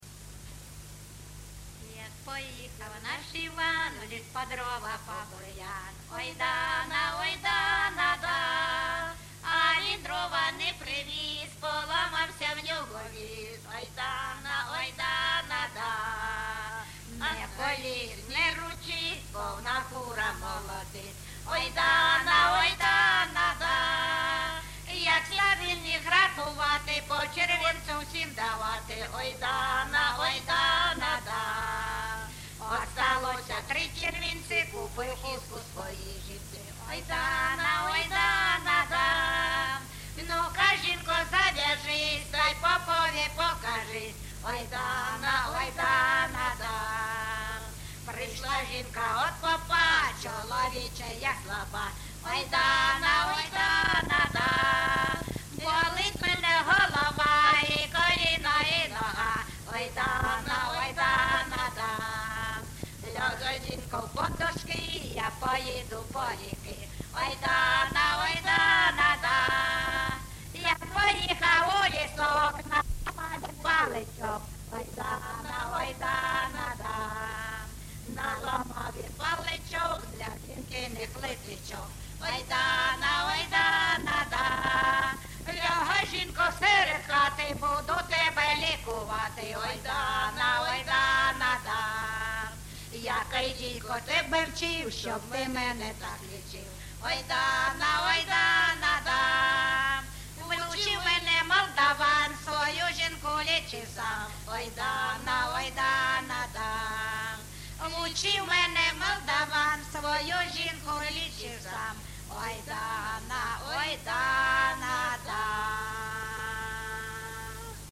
ЖанрЖартівливі
Місце записус-ще Новодонецьке, Краматорський район, Донецька обл., Україна, Слобожанщина